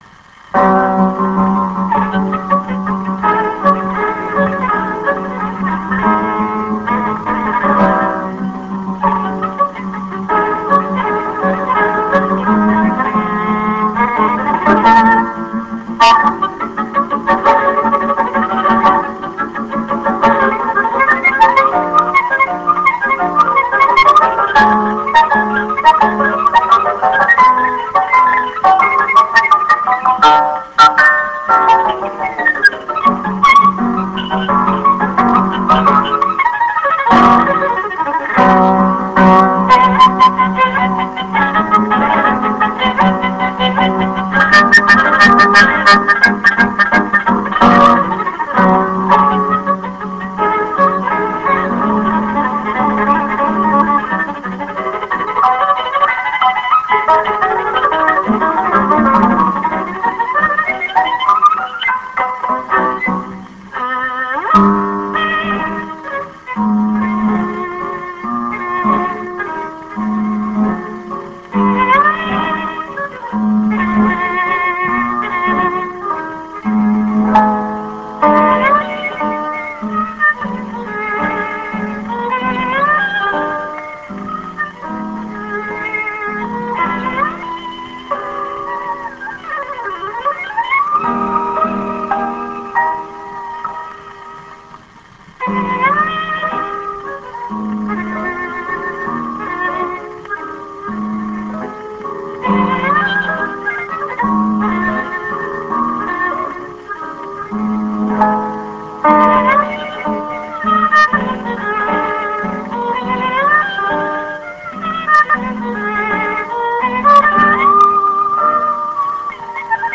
蓄音機から流れる音を録音してみました!!
ノスタルジックな雑音混じりの音楽を
バイオリン
12インチSP盤